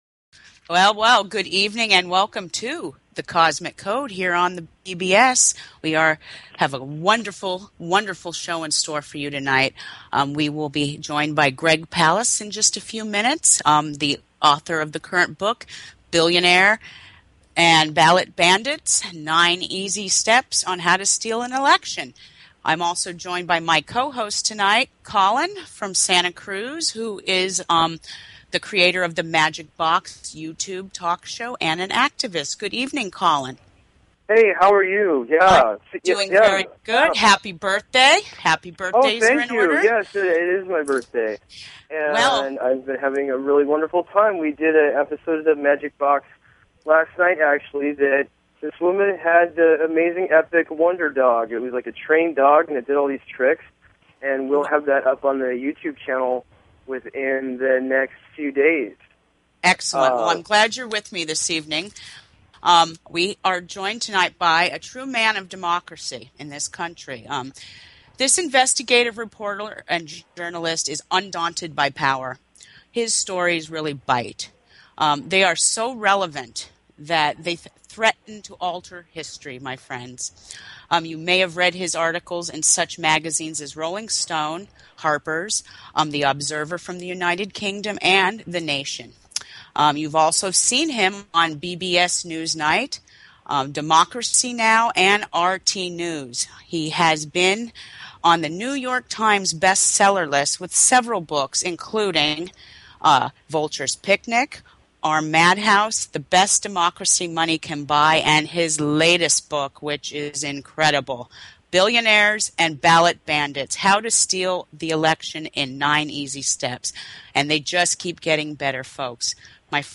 Talk Show Episode, Audio Podcast, Cosmic_Radio and Courtesy of BBS Radio on , show guests , about , categorized as